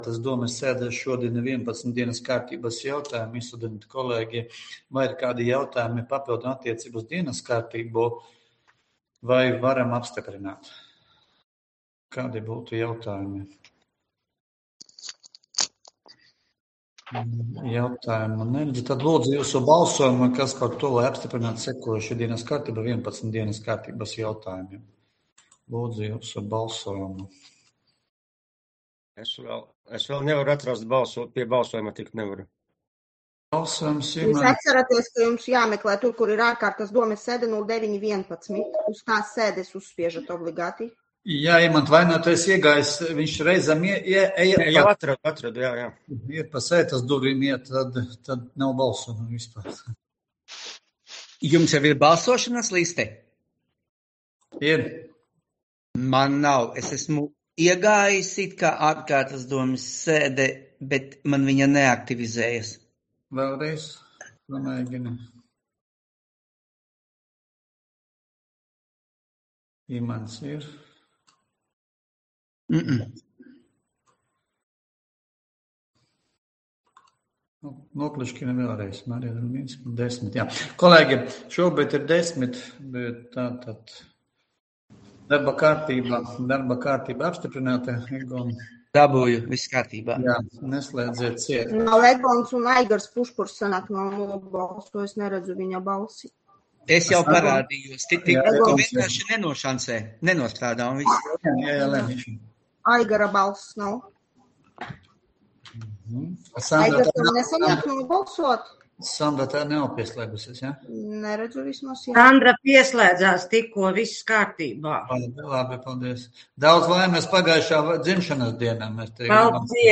9. novembra domes ārkārtas sēde